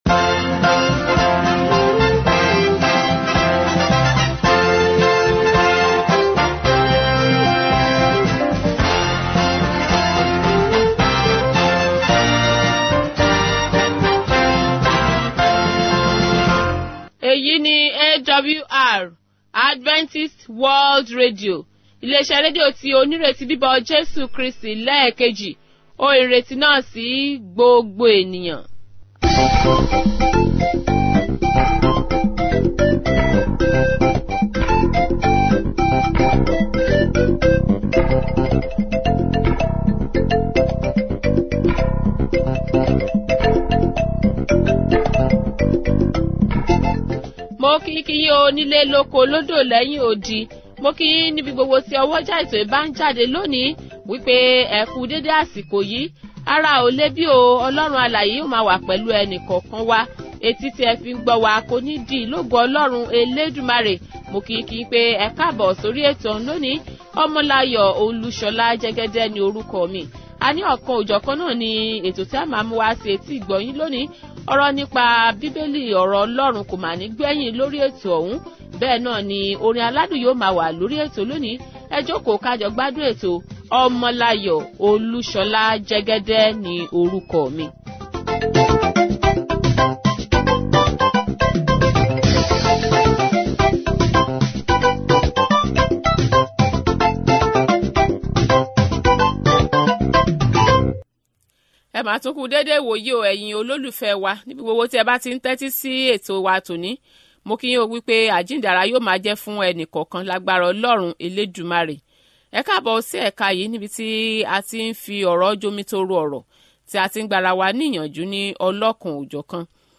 Daily Yoruba radio programs from Adventist World Radio